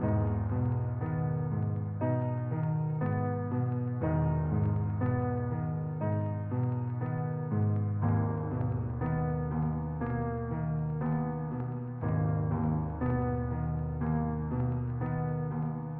A小调进阶钢琴
描述：只是一个钢琴循环。根据需要使用。第一个和最后几个样本已经消失，以避免弹出循环。
标签： 钢琴 情感 悲伤 进展慢
声道立体声